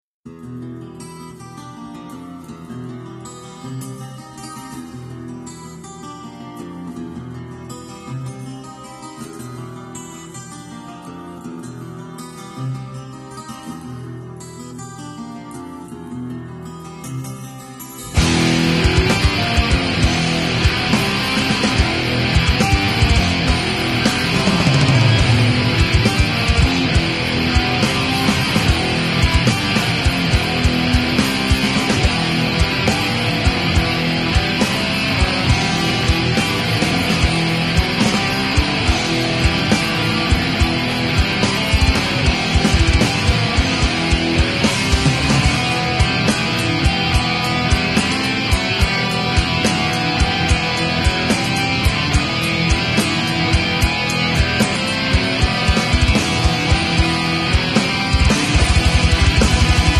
black metal and melodic death metal